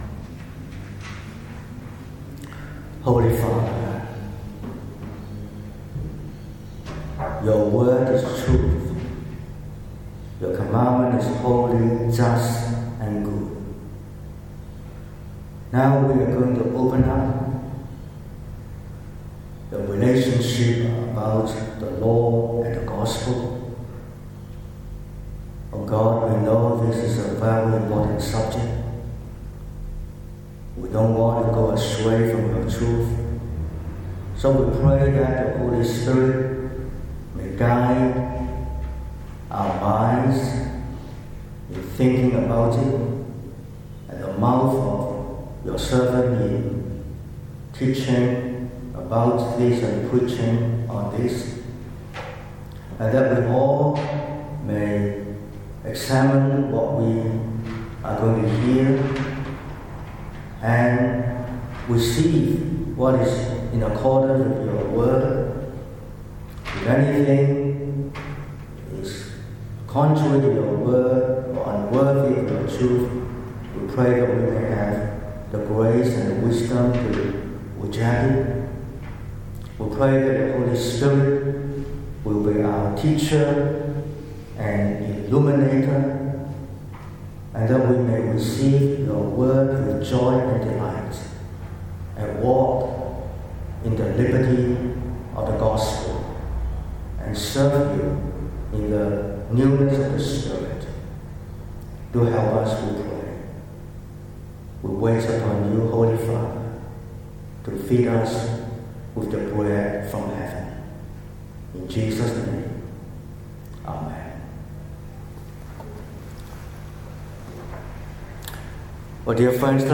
28/09/2025 – Evening Service: Made dead to the law